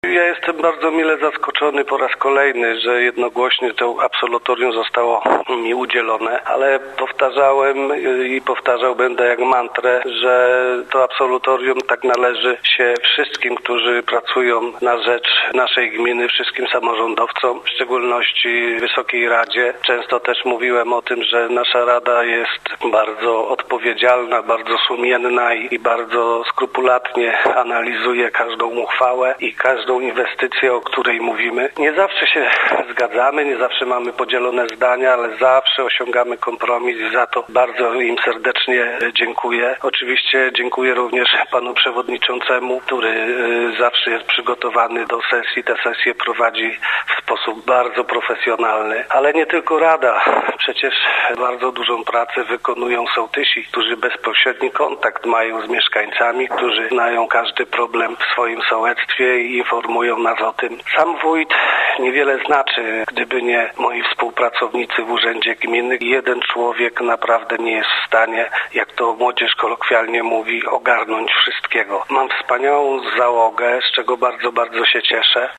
– komentował wójt, Dariusz Rejman.